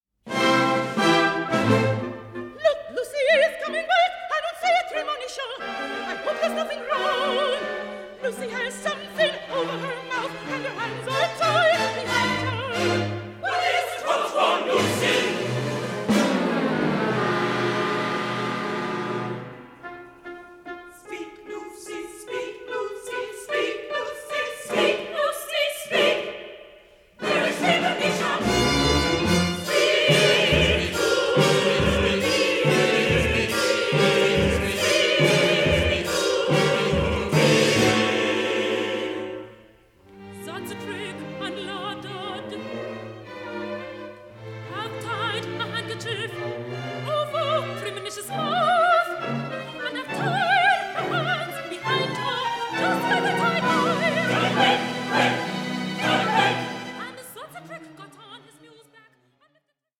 Opera in Three Acts
a buoyant blend of ragtime, vaudeville and grand opera